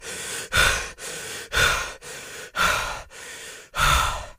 Звук запыхавшегося мужчины
Человек, люди